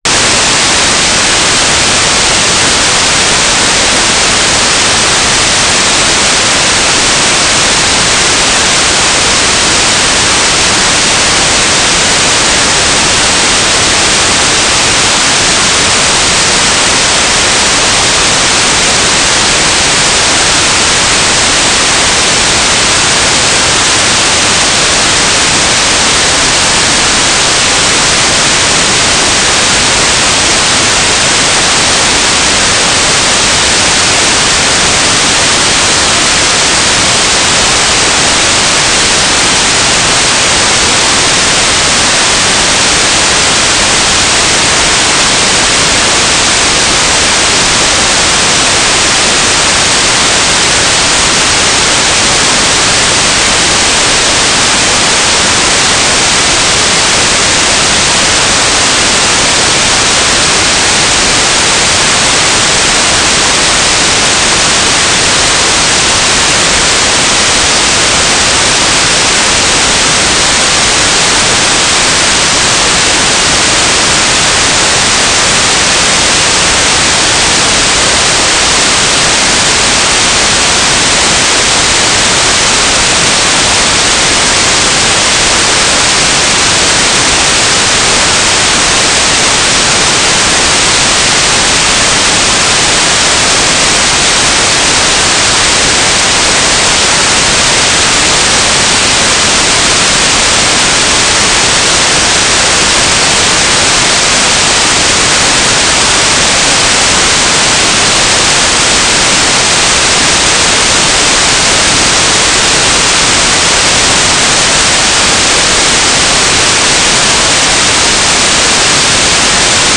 "waterfall_status": "without-signal",